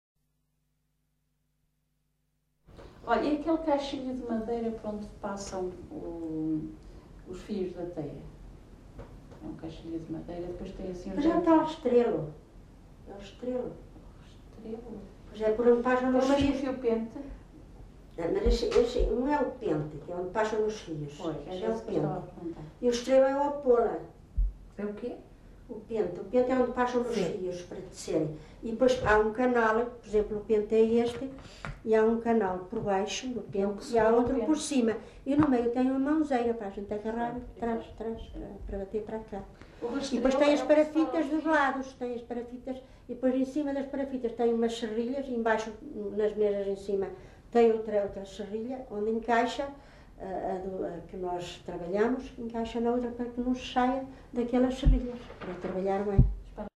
LocalidadeOuteiro (Bragança, Bragança)